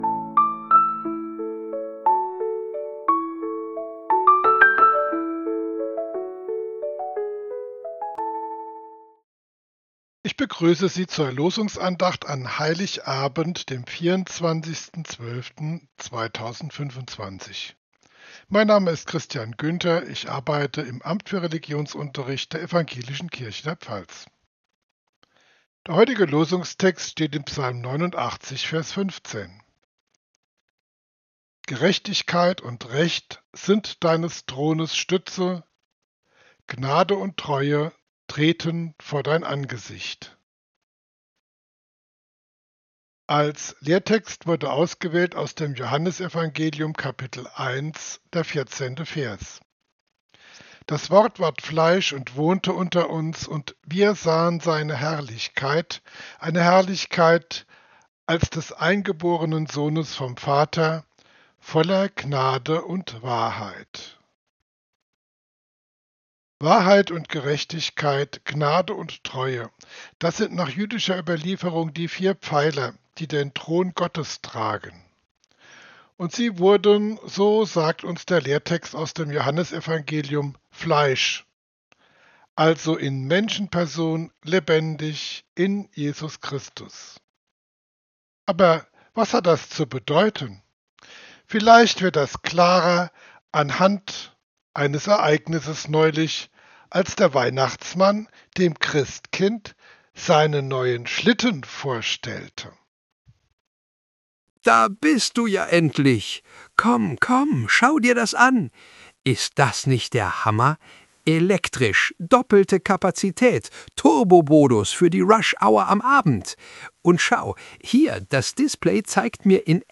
Losungsandacht für Mittwoch, 24.12.2025 – Prot.